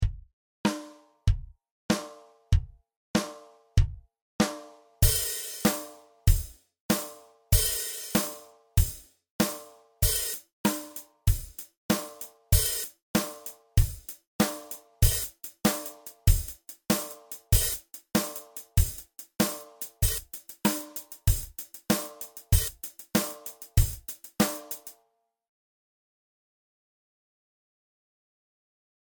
example on Garage Band drums.
First, I introduce the rock beat stripped down to its complete essentials: bass-SNARE-bass-SNARE. This beat is at 96 bpm and never changes throughout the song.
After two measures (8 beats total), I introduce a hi-hat, also on the beat. I emphasize the first beat, known as “the one” in musical parlance, with an open hi-hat sound.
Next, I subdivide the beat into two on the high-hat.
After two bars of the eighth-note beat, I play a triplet beat, where each beat is subdivided into three.
Finally, I conclude with a 16th-note beat on the hi-hat.
beat_examples.mp3